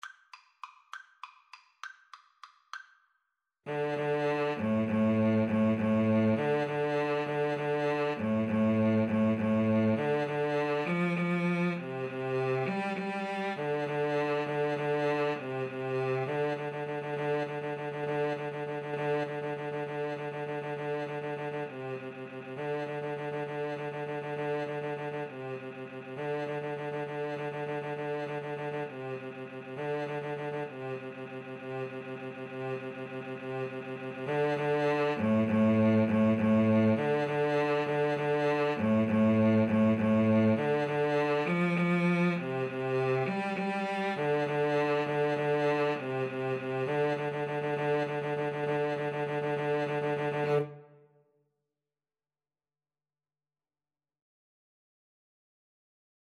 A film-score style piece
3/4 (View more 3/4 Music)
Fast and agressive =200
Film (View more Film Trumpet-Cello Duet Music)